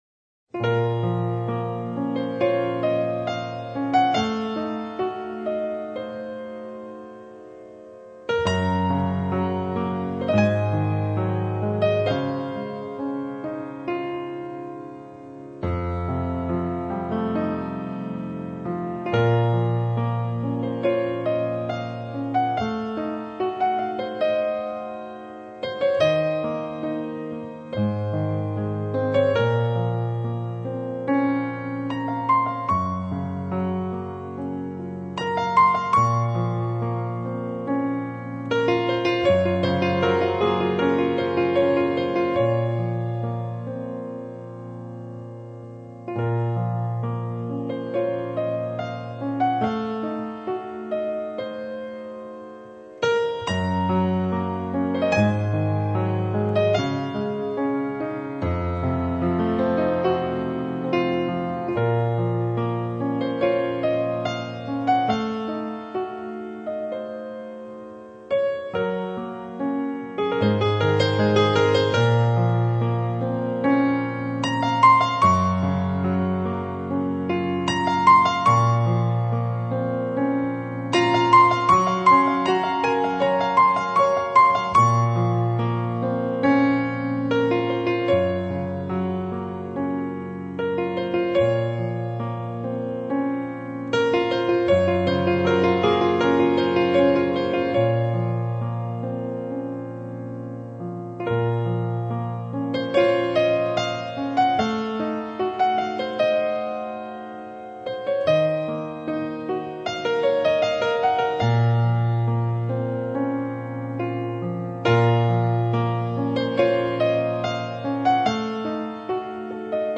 曲风缓慢恬静，带着丝丝淡淡的忧伤